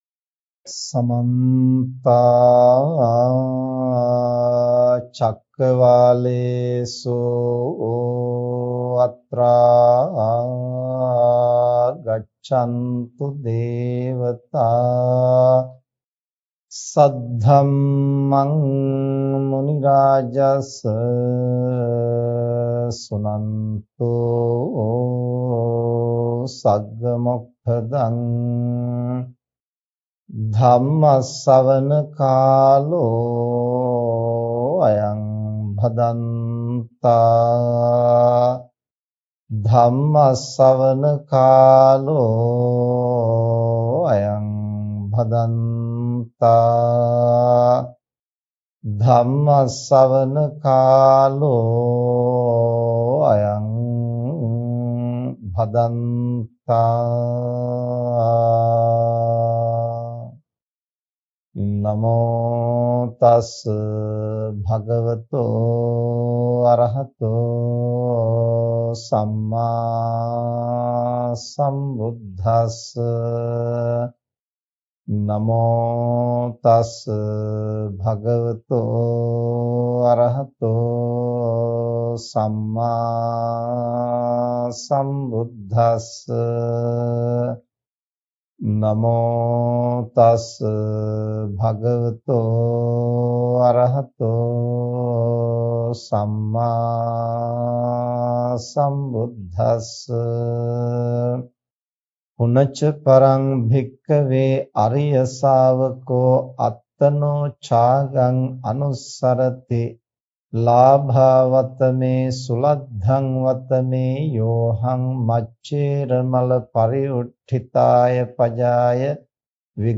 ''චාගානුස්සතිය'' ධර්ම දේශනාවේ ශබ්ද සංස්කරණය කල හඬ පටිය මෙම යොමුවෙන් ලබාගන්න.
චාගානුස්සතිය පිලිබඳ මෙම ධර්ම දේශනාව වර්ථමාන කාලයට ඉතාම ගැලපෙන දේශනාවක් වන්නේ පහත සඳහන් මූලික කරුණු නිසාවෙනි.